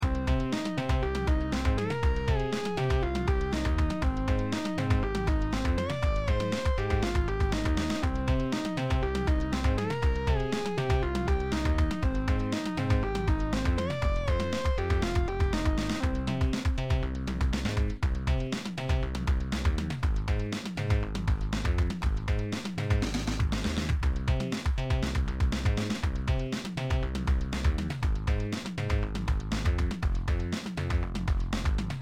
16-bit art and music